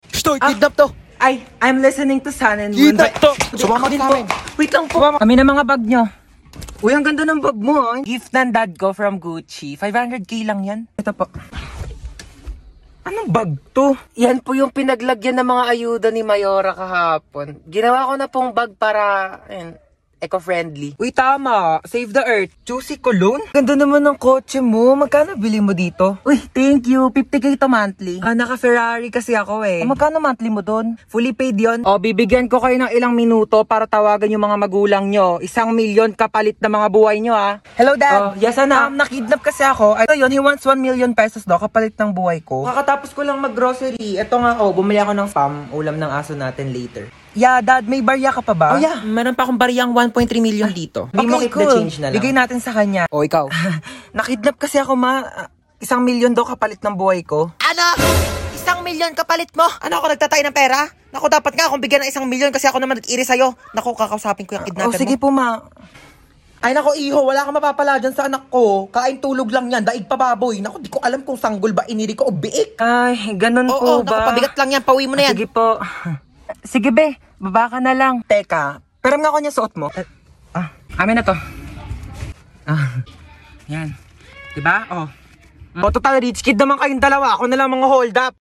♡Classic slime with shaving foam♡